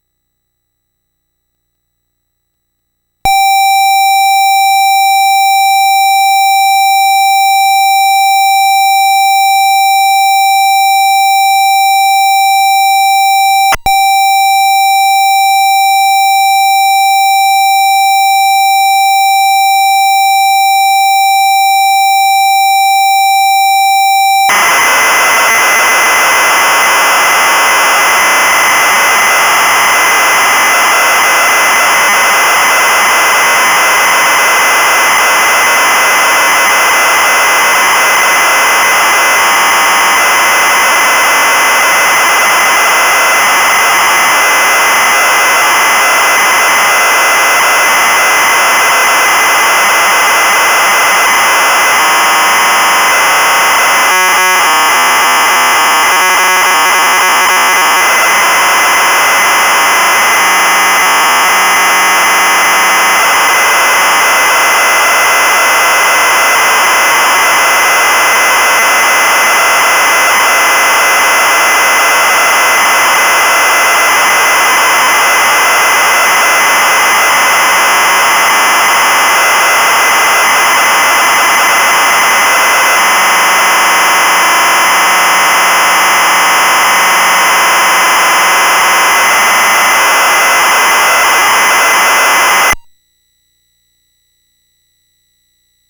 Cassette copy of "Lemonade Stand" from the vintage disk
Ran a cable from Cassette Out port of my enhanced Apple IIe clone to mic-in of Edirol sound recorder
Single BEEP heralded start of the recording, another BEEP the finish.
Either a mono or stereo lead will work, as I've duplicated the audio to both channels.
Cassette_Lemonade_from_Elementary_My_Dear_Apple.flac